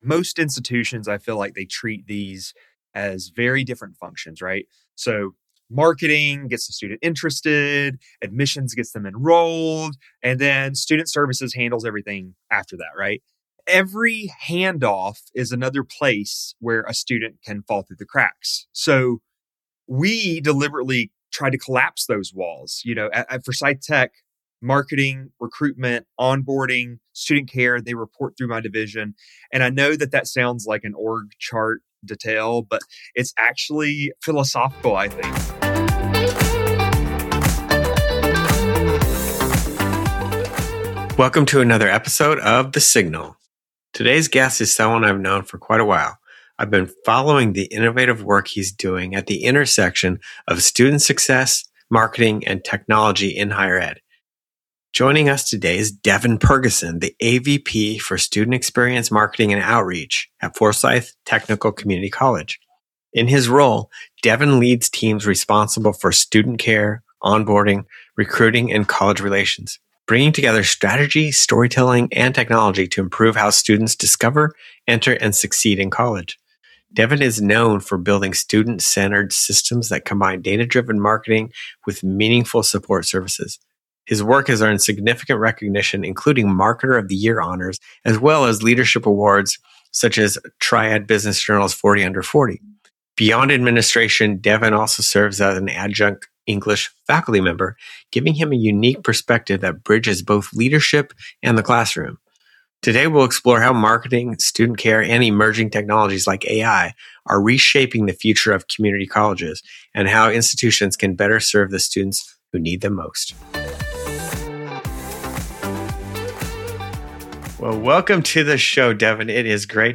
Each episode features interviews with leading experts, educators, technologists and solution providers, who share their insights on how technology can be used to improve student engagement, enhance learning outcomes, and transform t